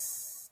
Southside Open Hatz (18).wav